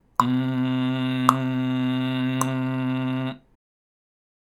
今回は、「んー」と鼻から声を出し、その音を途切れさせないように舌打ち（または舌叩き）を行います。
※見本のグーの声(舌叩き編)